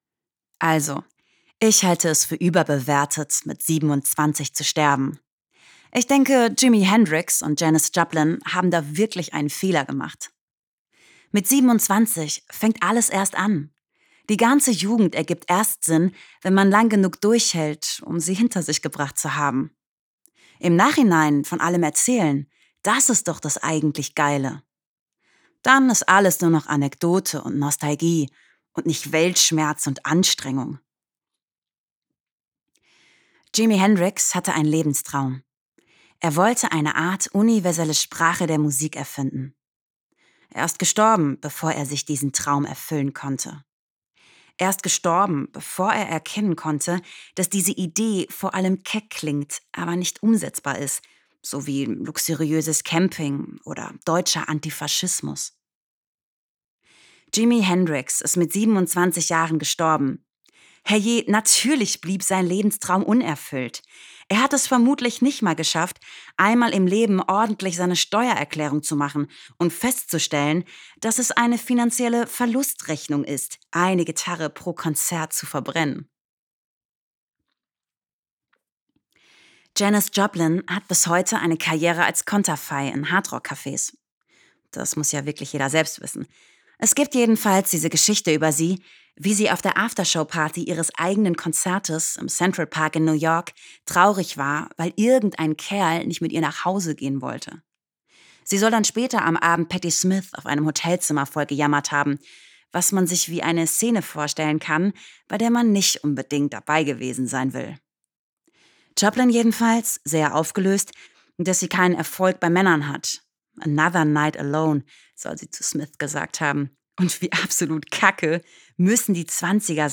Sprecherin
Sprechproben